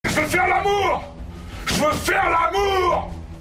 wood4.ogg